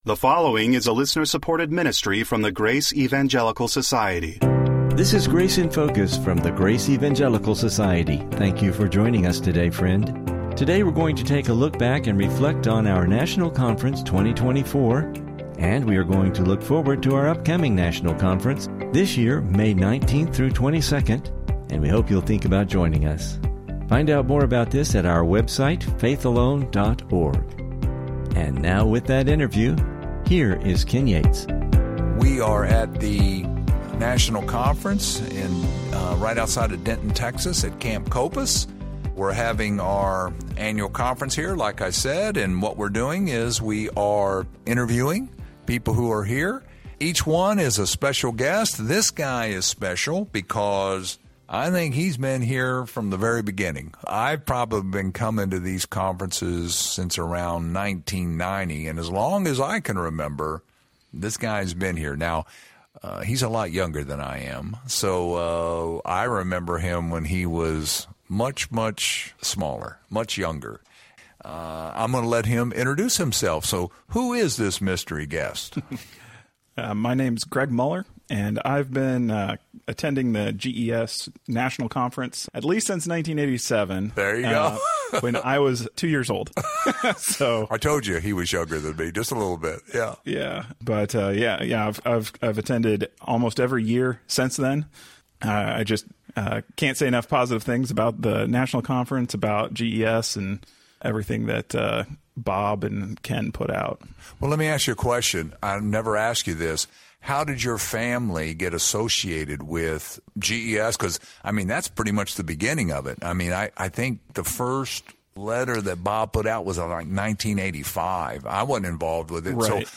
Interview National Conference 2024 Attendee